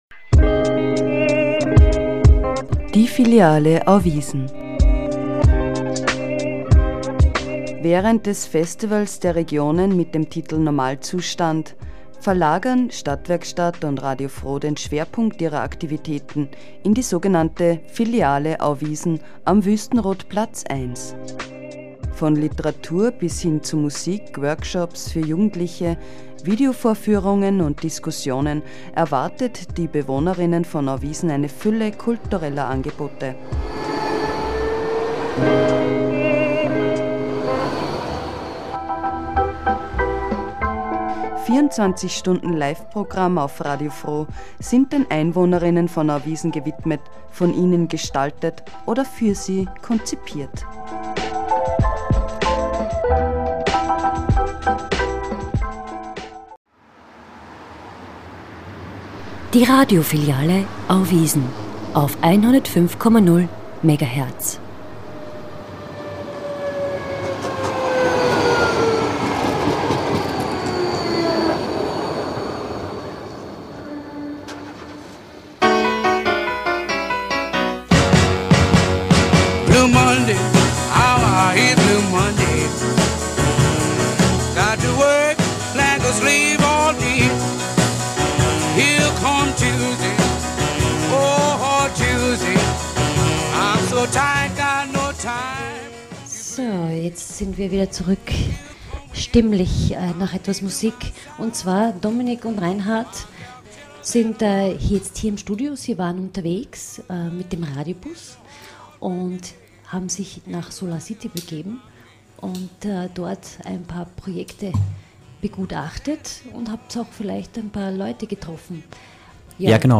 Mai Teil 2 Artist: Radio FRO 105,0 MHZ Title: Live aus Auwiesen- Die Mobile Filiale unterwegs Length: 43:32 minutes (39.86 MB) Format: Stereo 44kHz Filiale Unterwegs: Bycicle Tron, Uralubsabend am kleinen Weikerlsee und Story Tailors.